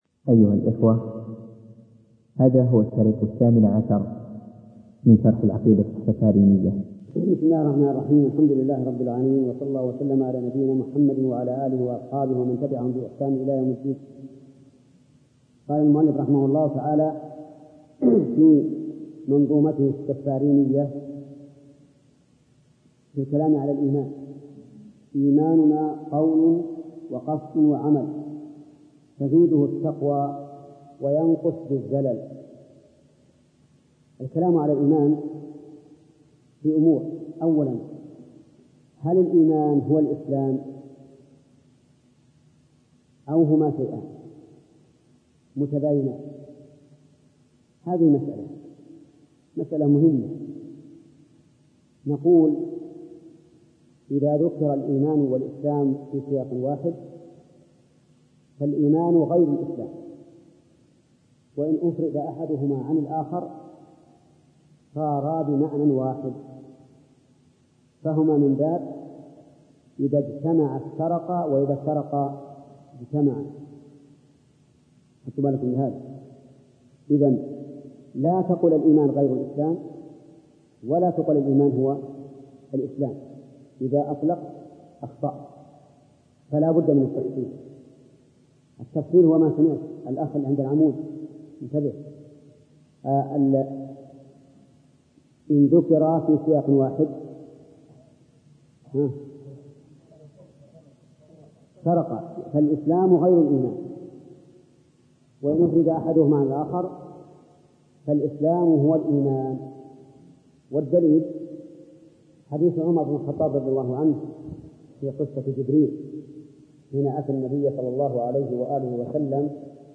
الدرس الثامن عشر - فضيلة الشيخ محمد بن صالح العثيمين رحمه الله